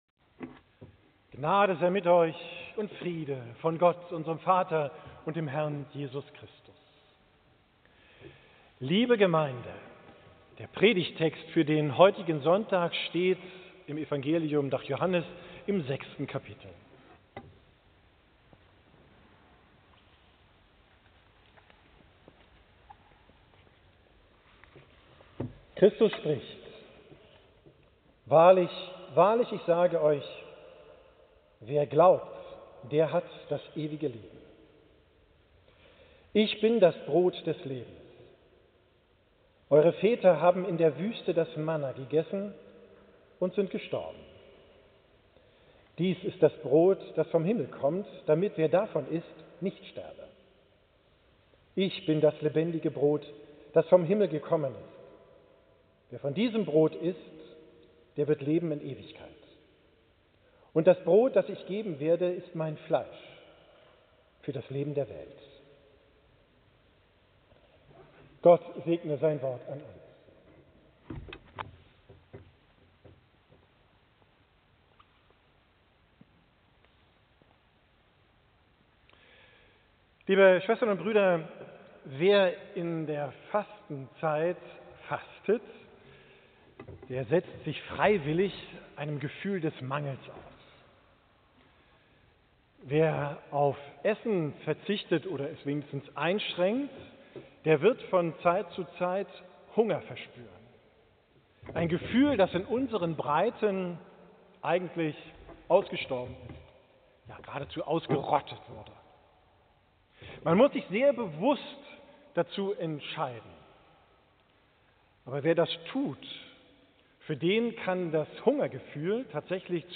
Predigt vom Sonntag Laetare, 30.